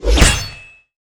EnemyKill.wav